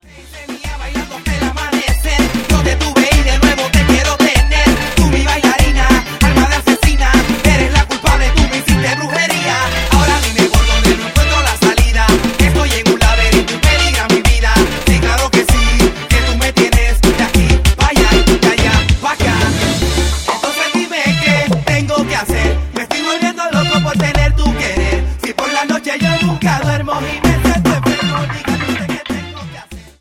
Reggaeton Charts - Januar 2009